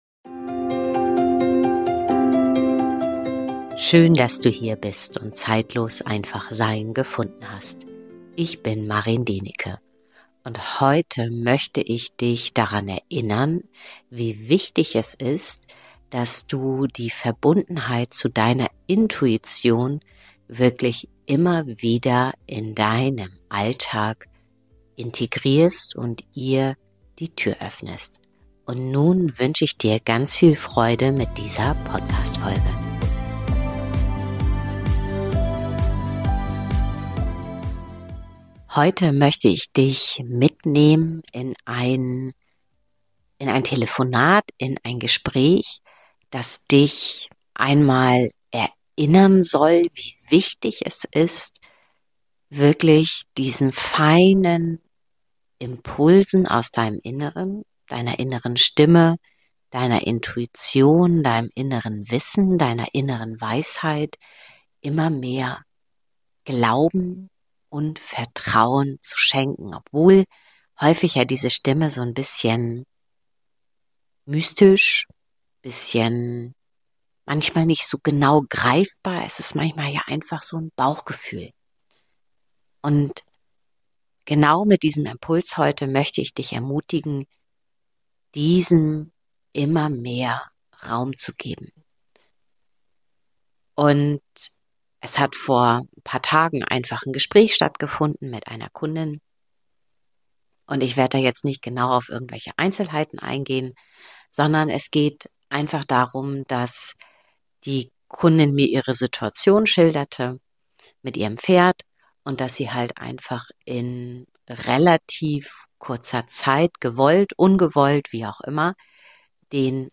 In der heutigen Folge möchte ich mit Dir in das Feld der Intuition eintauchen. Ich teile mit Dir ein kurzes Gespräch mit einer Kundin, die ihrer Intuition nicht gefolgt ist. Jeder Pferdehalter kennt das Thema des Stallwechsels.